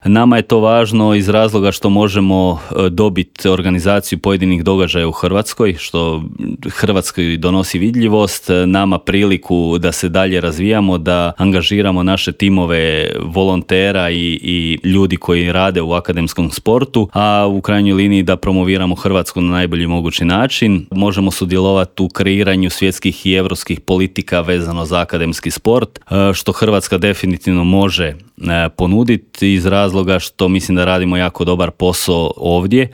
U intervjuu Media servisa